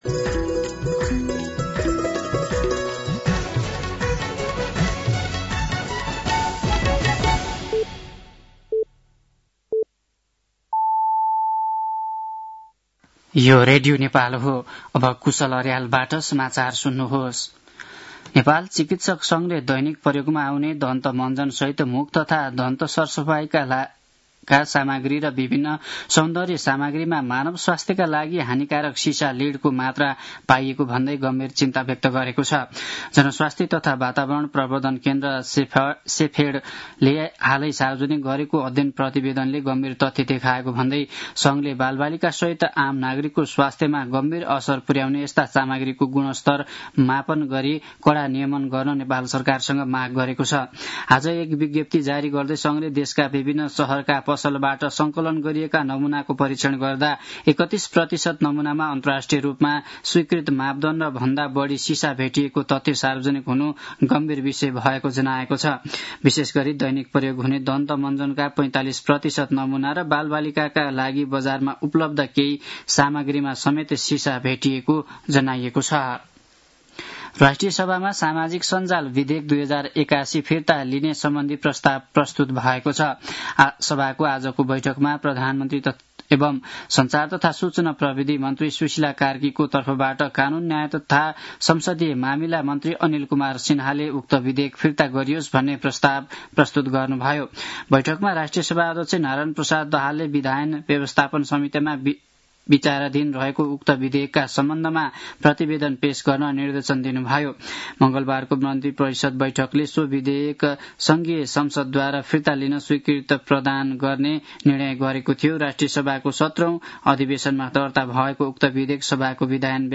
साँझ ५ बजेको नेपाली समाचार : २३ माघ , २०८२